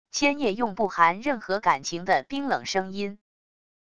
千夜用不含任何感情的冰冷声音wav音频